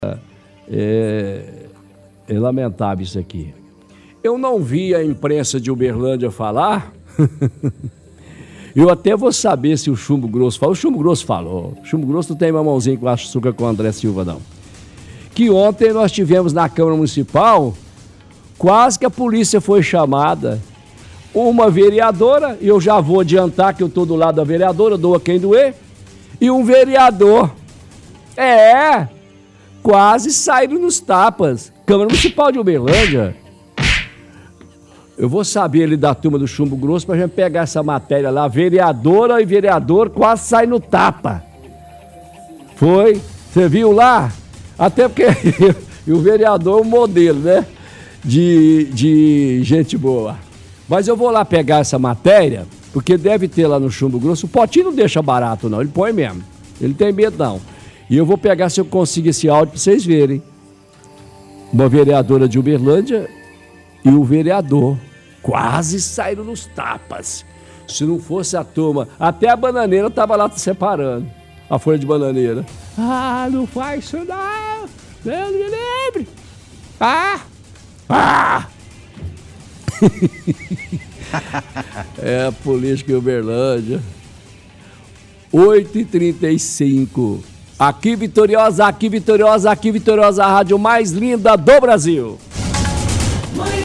– Transmissão de vídeo de discurso do vereador Abatênio e da vereadora Cláudia Guerra falando na tribuna durante sessão da câmara.